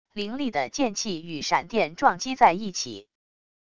凌厉的剑气与闪电撞击在一起wav音频